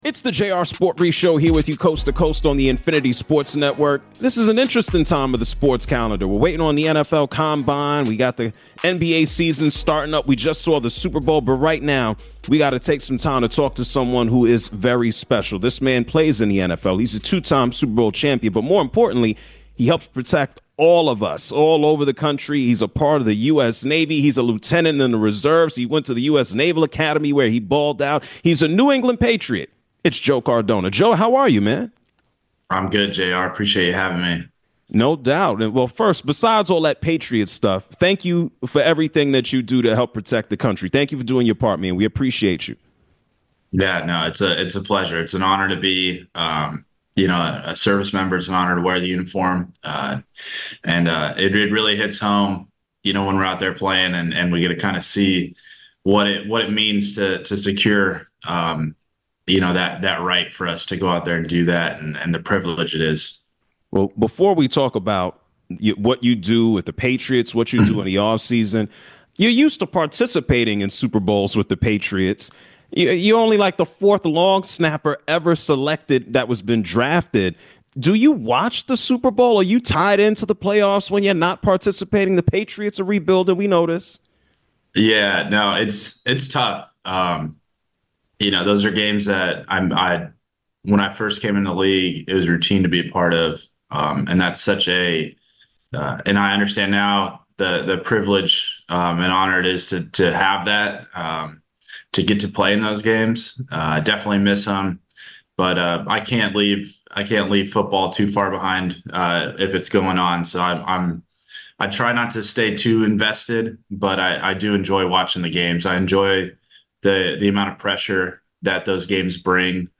New England Patriots Long-Snapper Joe Cardona Joins the Show!